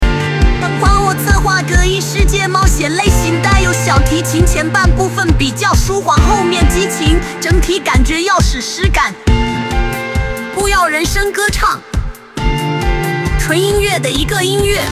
帮我策划个异世界冒险类型带有小提琴前半部分比较舒缓后面激情，整体感觉要史诗感，不要人声歌唱，纯音乐的一个音乐